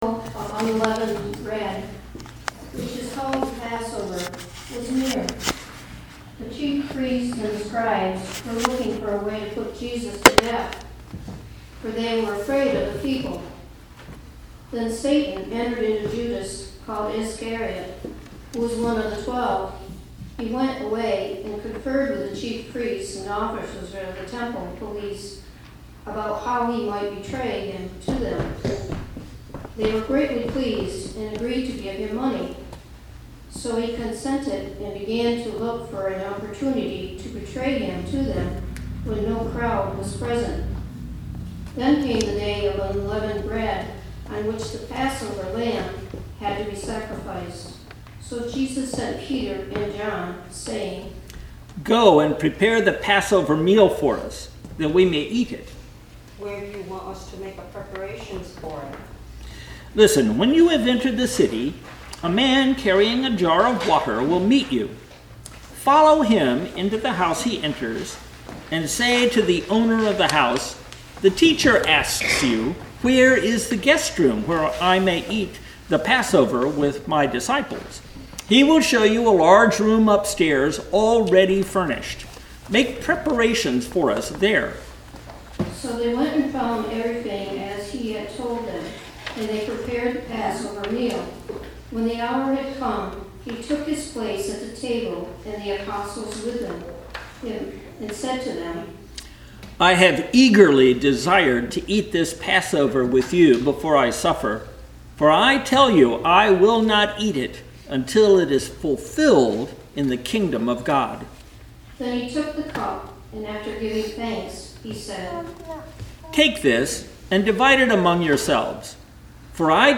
palm-sunday-passion-readings-2019.mp3